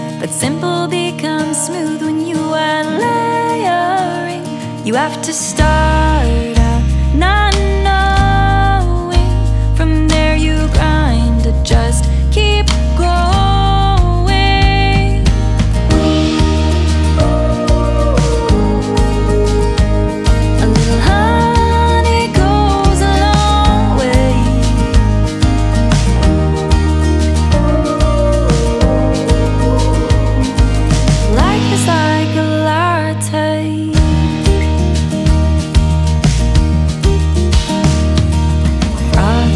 Folk Pop
The song is both heartwarming and reflective.